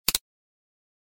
back-button-click.wav